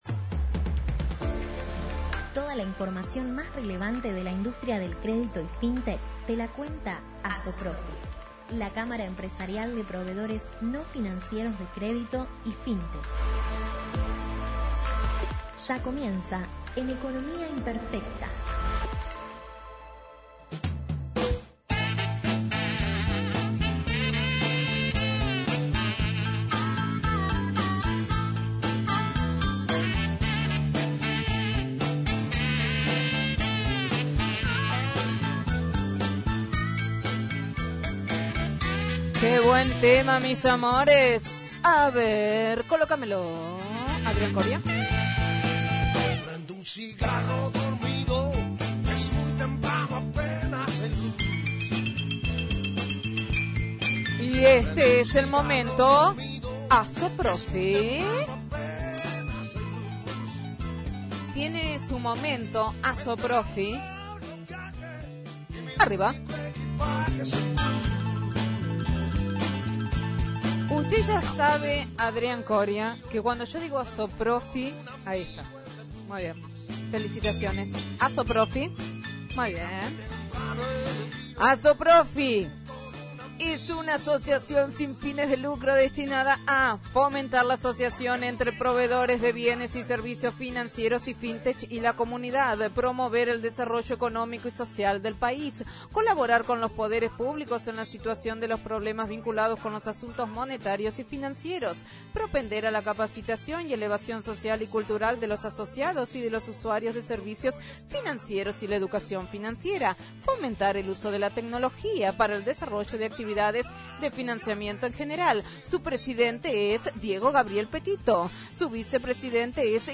ASOPROFI – COLUMNA RADIAL – RADIO AM 1420 Viernes 18/06/2021 ” Como financian sus actividades los proveedores no financieros de Créditos”